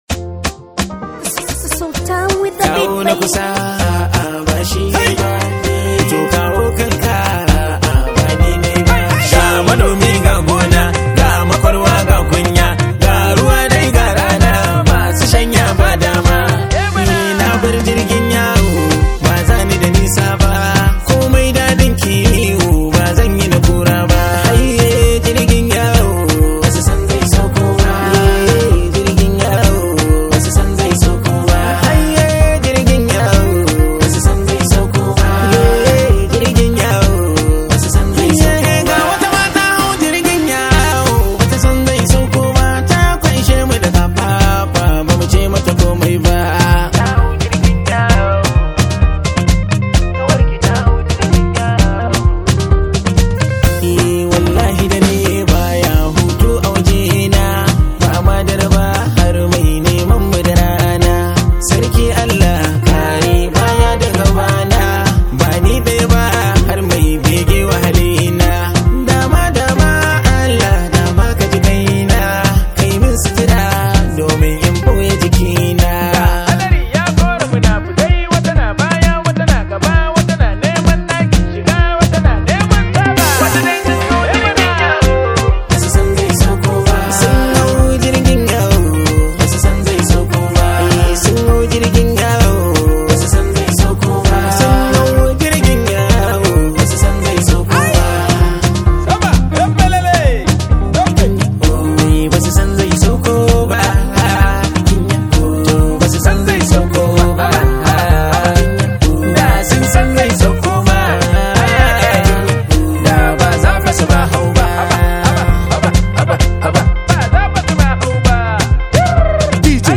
Hausa Songs
hausa hip hop